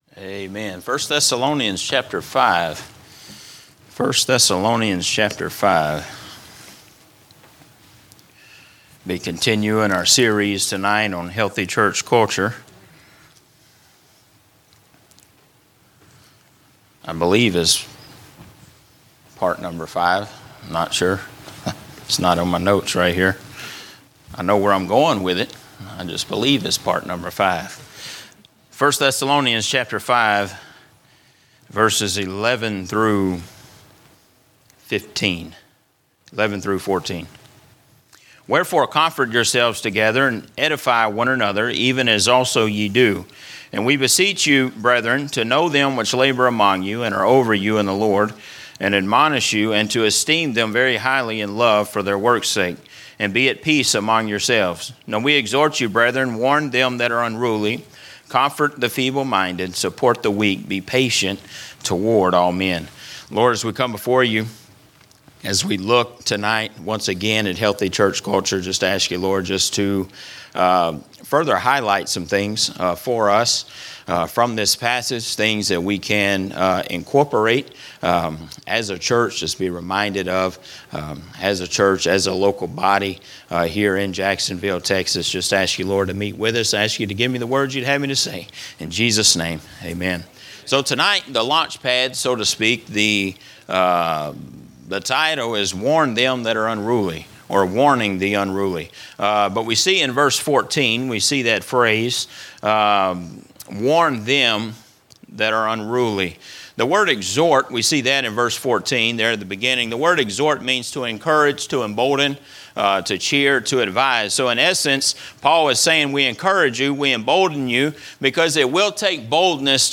A message from the series "General Preaching." A look at the six trials of Jesus leading up to His crucifixion